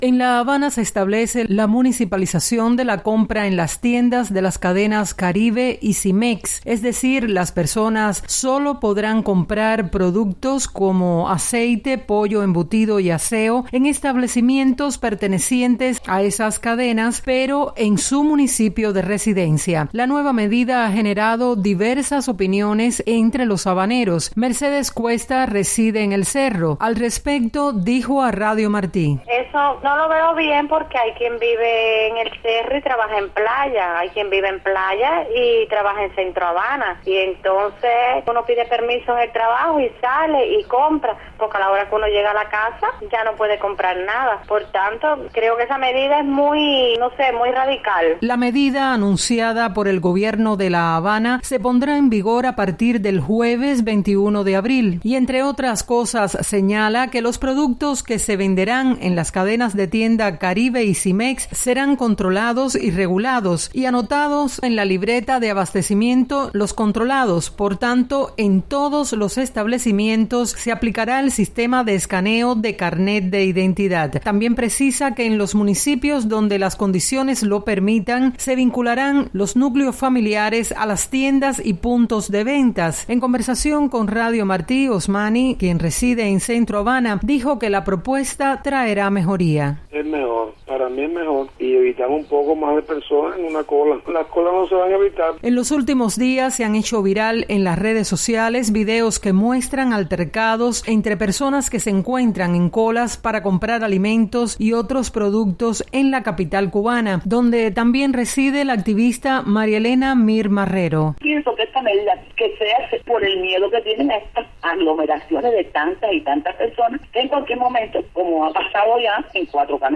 Capitalinos entrevistados por Radio Televisión Martí no están conformes con esta municipalización, que supone un lastre más en la pesada carga para satisfacer las necesidades de sus familias en medio de la crisis económica que vive el país.